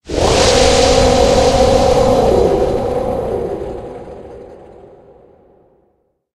Cri de Pyrobut Gigamax dans Pokémon HOME.
Cri_0815_Gigamax_HOME.ogg